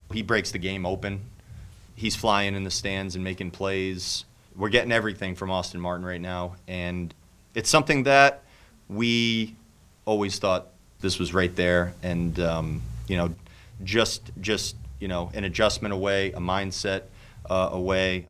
Twins manager Rocco Baldelli says it is great to see Austin Martin playing well.